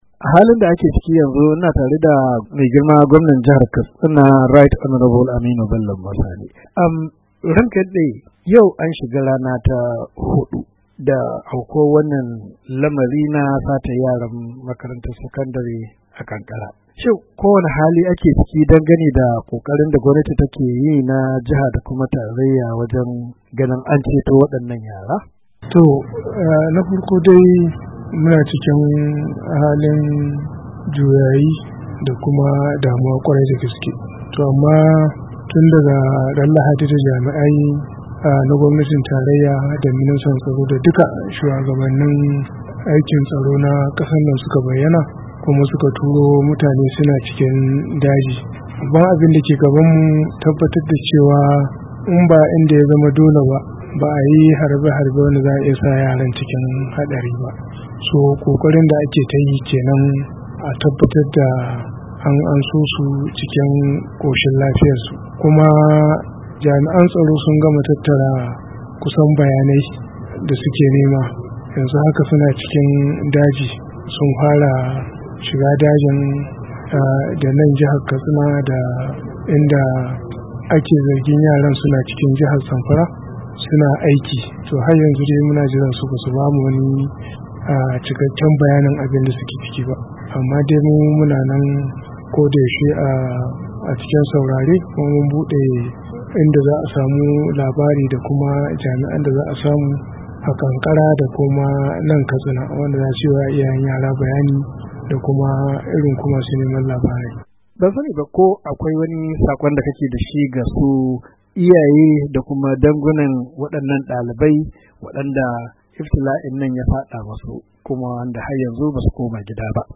Hira da gwamna Aminu Bello Masari:2:58"